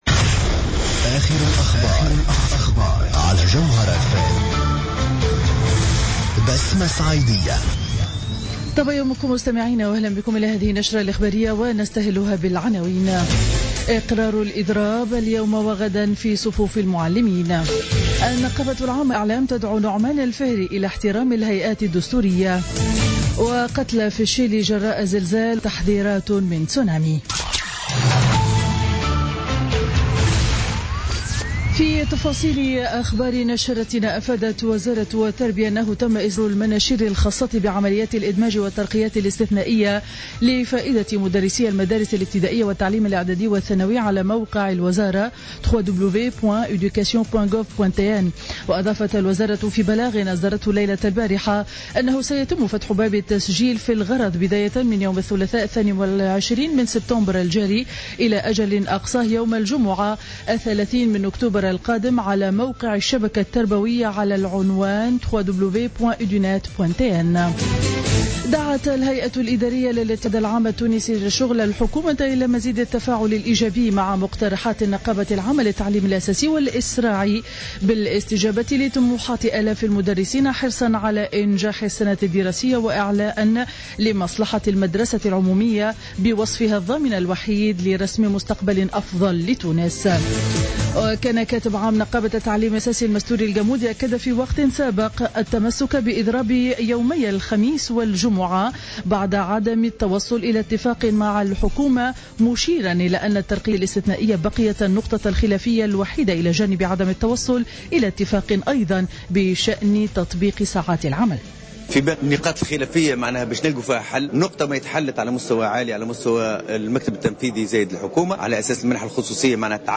نشرة أخبار السابعة صباحا ليوم الخميس 17 سبتمبر2015